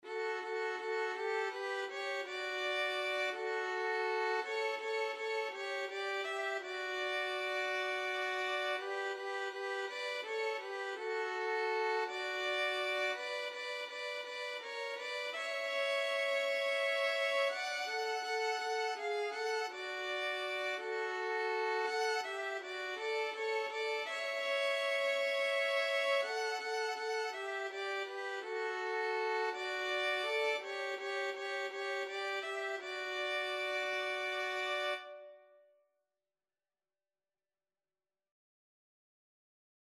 Christian
6/8 (View more 6/8 Music)
Classical (View more Classical Violin Duet Music)